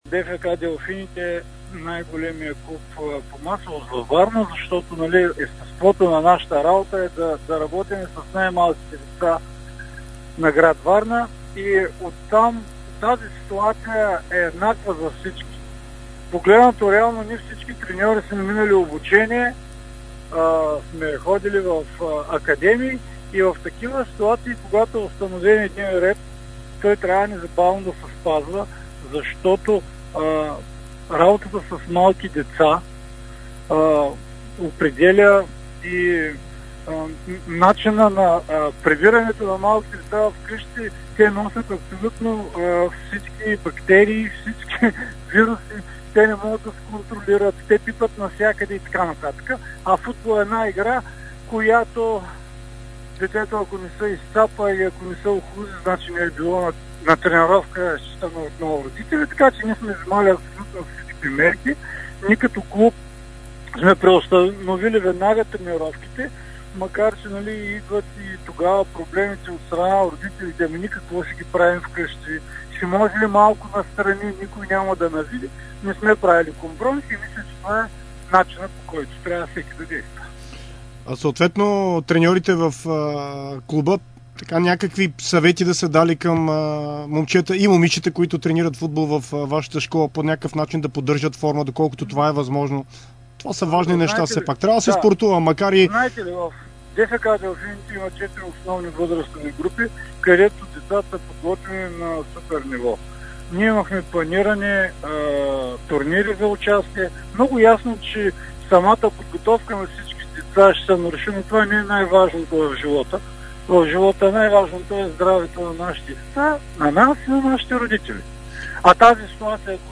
интервю за Дарик радио и dsport